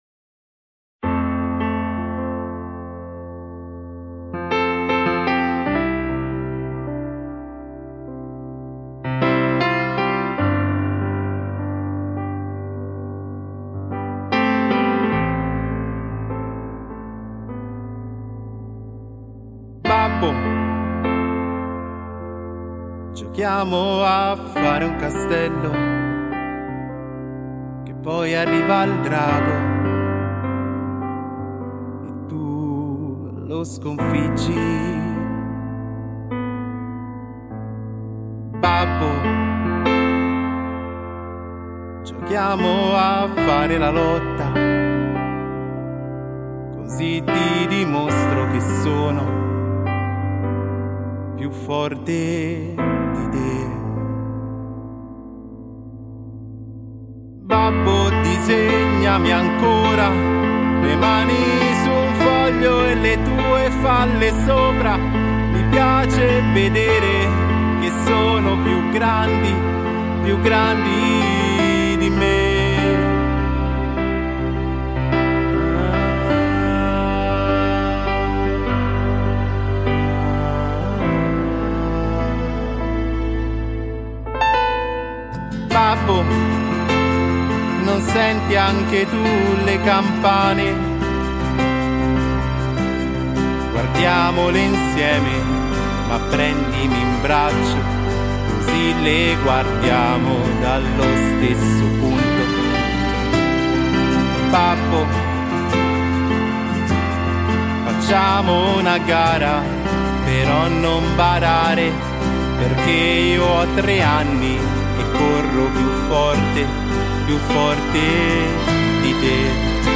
GENERE Pop / Singer / Cantatuorale